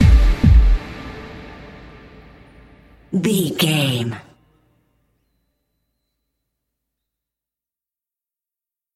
Music
Ionian/Major
D
synthesiser
drum machine
tension
ominous
dark
suspense
mysterious
haunting
creepy
spooky